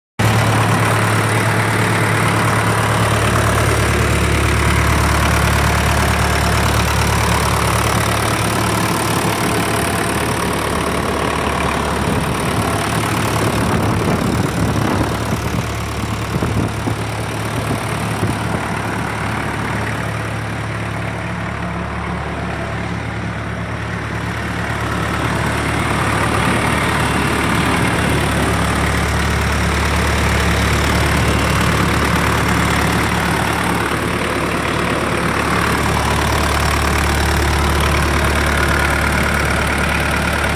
(старая техника, 6000 моточасов)
Вот такой звук получает для анализа система.
·        🌡 Температура двигателя 68.0°C (прогретый двигатель)
Повышенный механический гул
Свист под нагрузкой